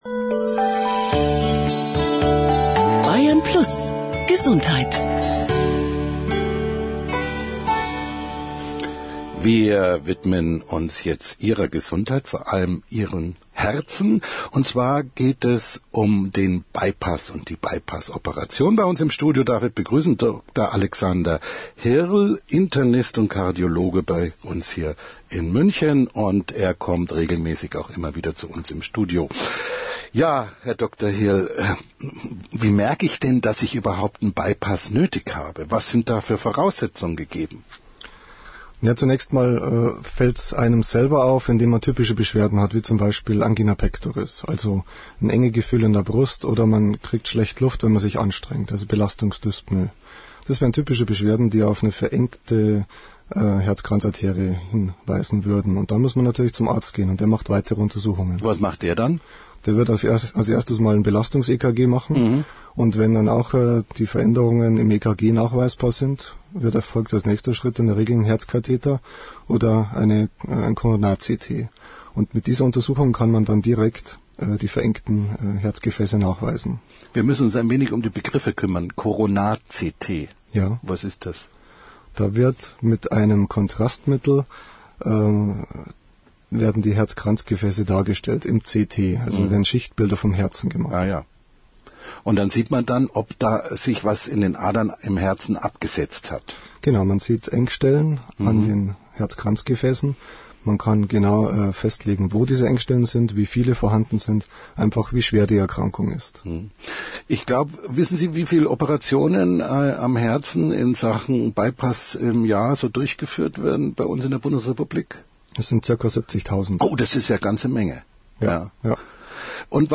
LIVE-INTERVIEW
interview-bypass.mp3